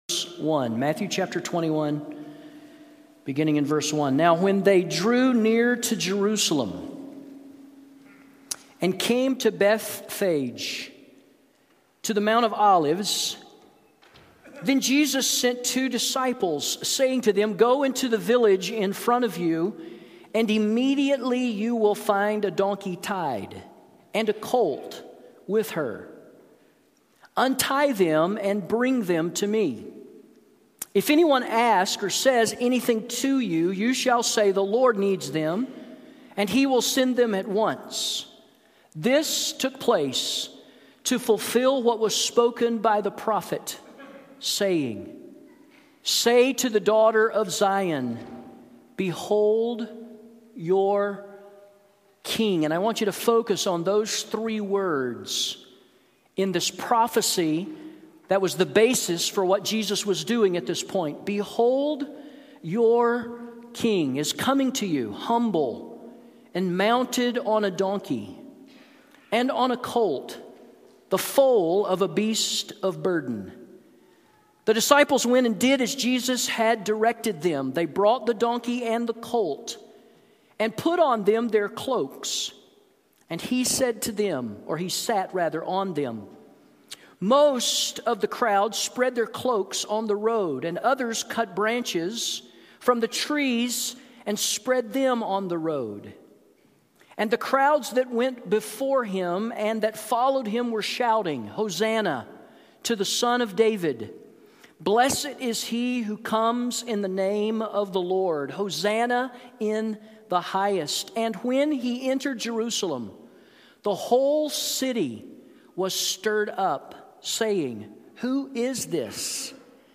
Sermons Archive - Page 30 of 118 - South Canyon Baptist Church